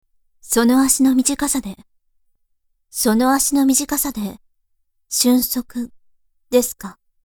【サンプルセリフ】
そこに、透明感のあるお声のイメージもプラスして、凛とした雰囲気で設定してみました。